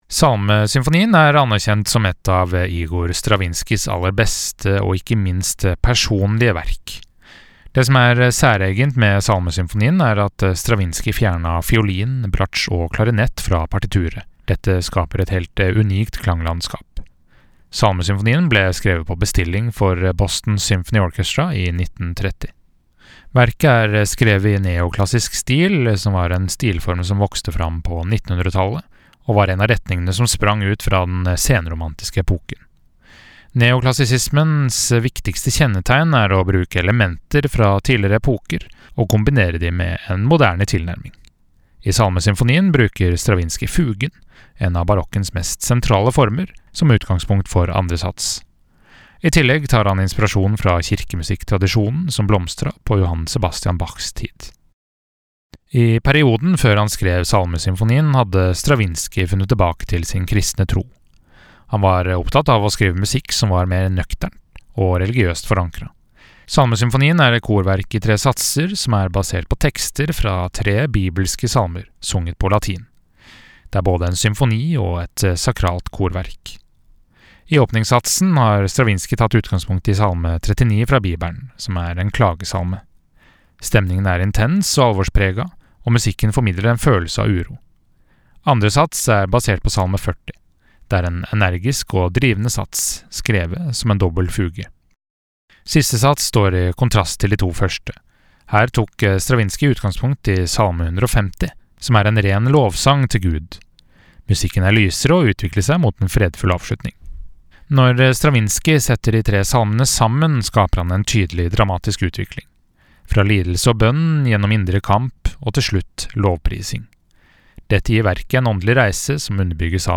VERKOMTALE-Igor-Stravinskijs-Salmesymfoni.mp3